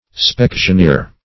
specksioneer - definition of specksioneer - synonyms, pronunciation, spelling from Free Dictionary
Search Result for " specksioneer" : The Collaborative International Dictionary of English v.0.48: Specksioneer \Speck`sion*eer"\, n. The chief harpooner, who also directs in cutting up the speck, or blubber; -- so called among whalers.
specksioneer.mp3